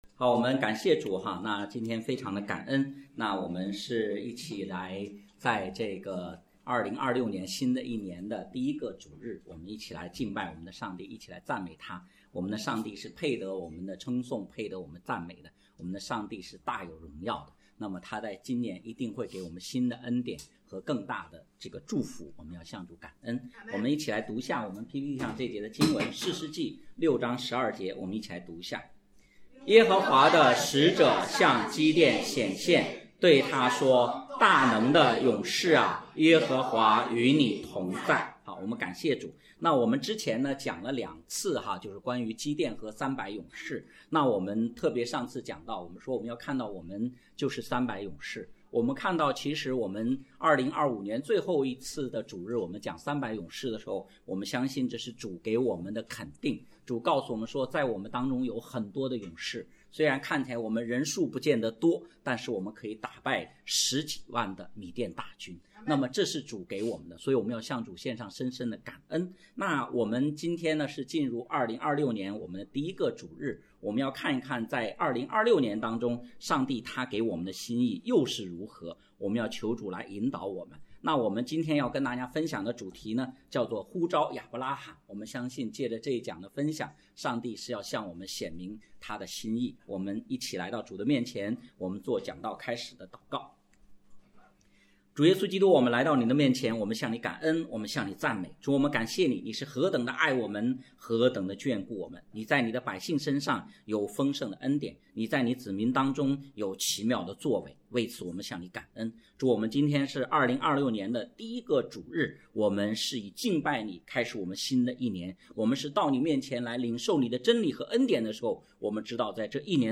讲道录音